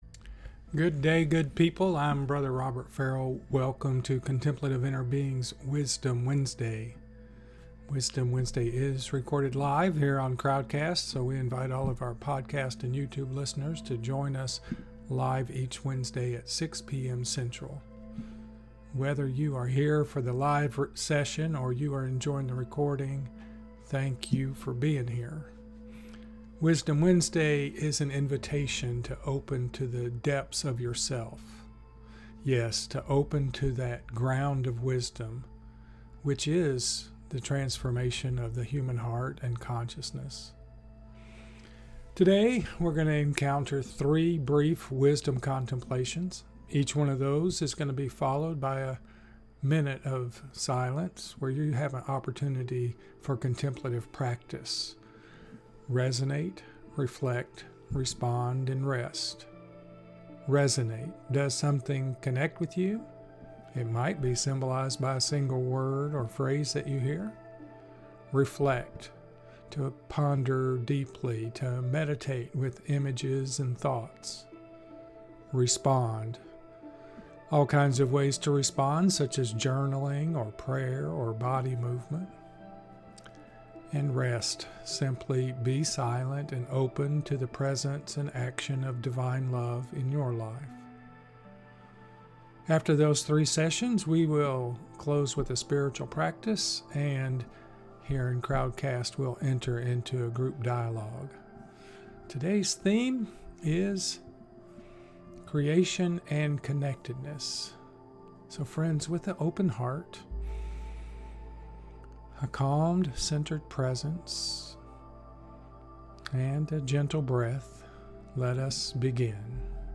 Wisdom Wednesday is recorded live on Crowdcast.